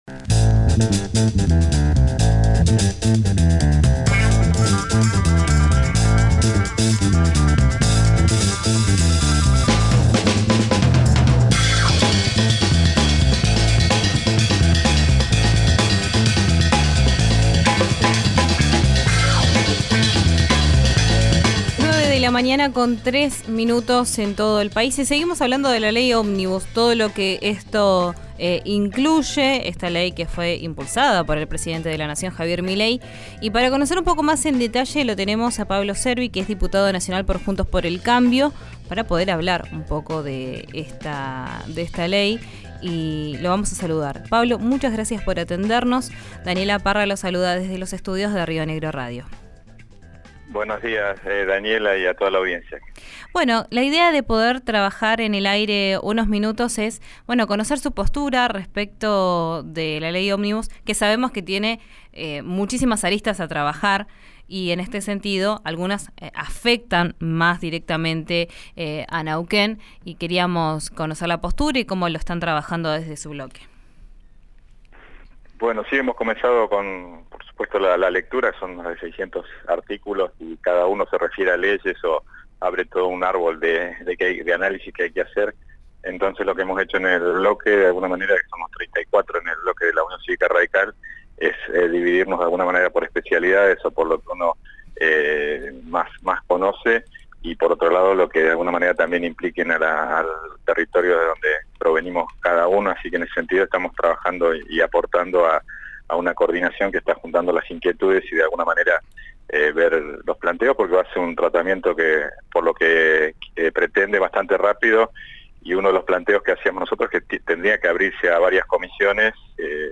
El diputado nacional de Juntos por el Cambio opinó que el proyecto tiene ciertas inconsistencias y desprolijidades. Escuchá la entrevista completa en RÍO NEGRO RADIO.
Así lo aseguró el diputado nacional por Neuquén, Pablo Cervi, en diálogo con RÍO NEGRO RADIO.